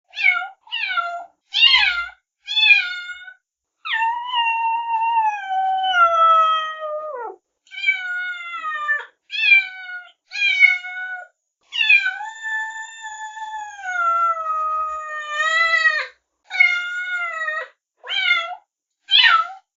Miauuuuuuuuuu
katzen-miauen-spiele-das-ab-und-deine-katze-kommt-sofort-zu-dir-katzensound-katzenliebe.mp3